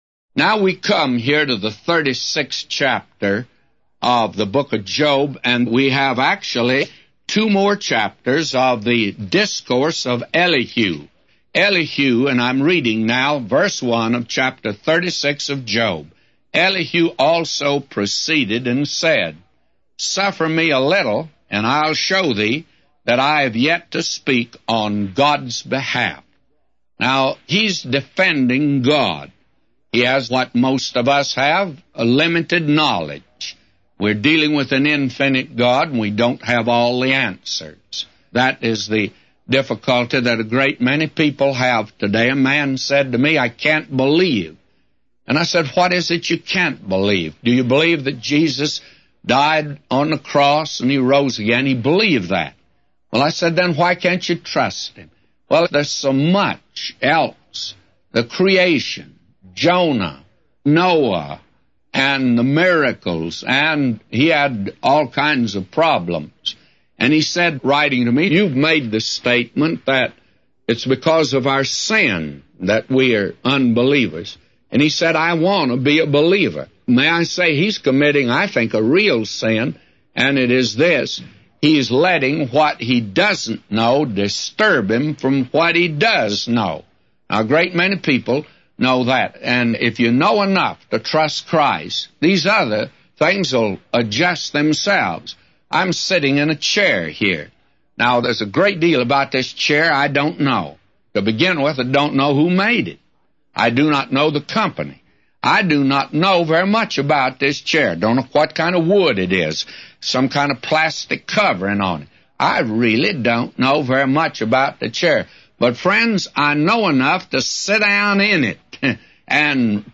A Commentary By J Vernon MCgee For Job 36:1-999